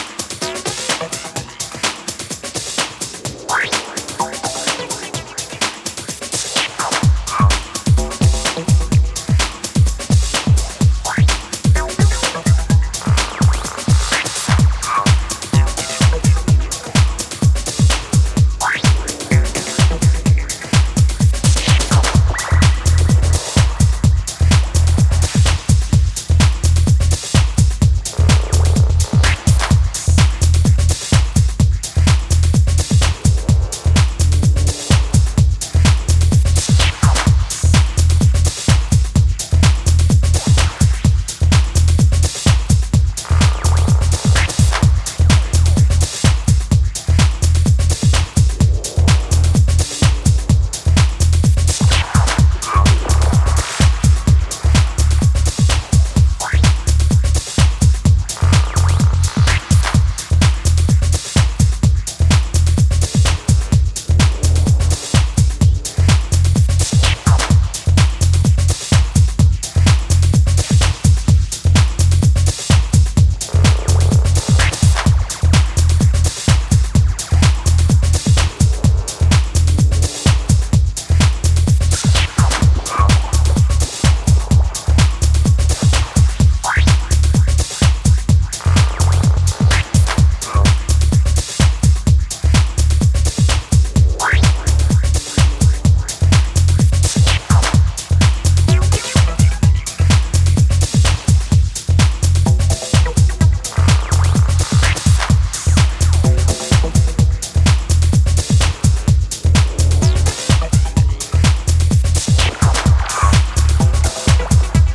hypnotic tech house aesthetic